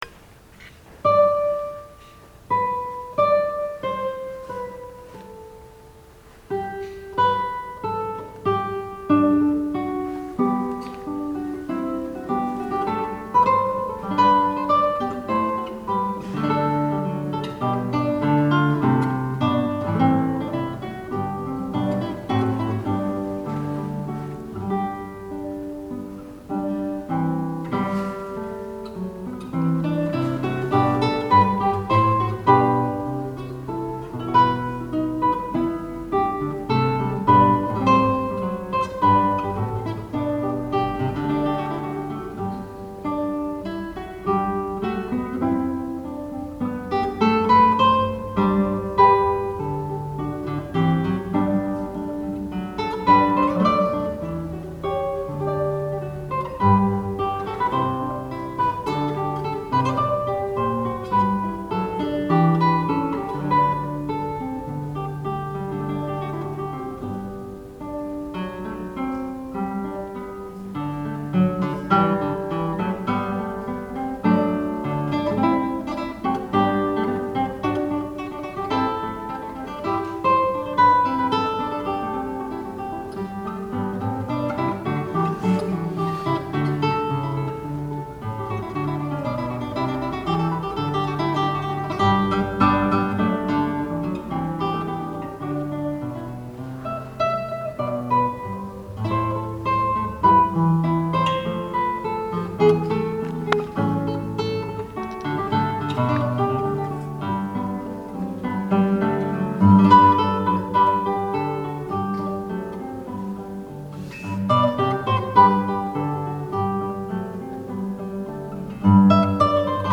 Secretaría de Cultura- Kit de prensa- Guitarristas mexicanos ofrecieron recital clásico durante la segunda temporada del Festival Divertimento